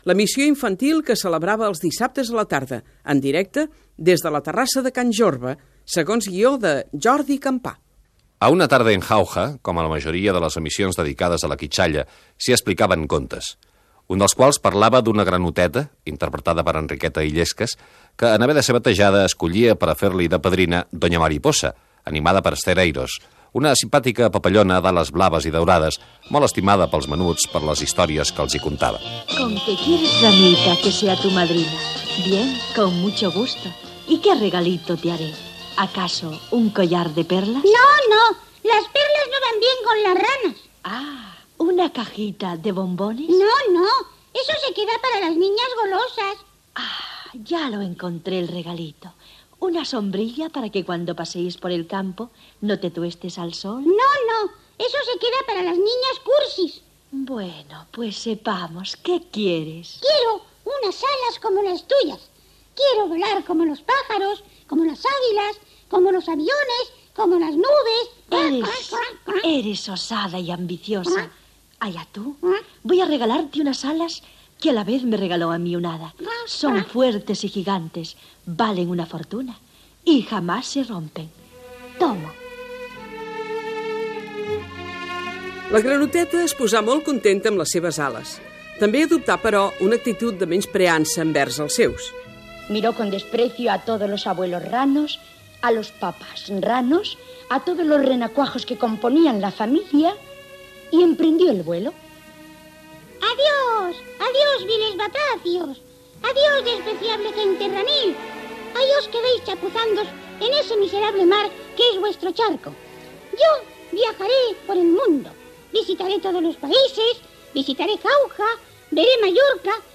Fragment del programa "Una tarde en Jauja" de Ràdio Miramar
Divulgació
FM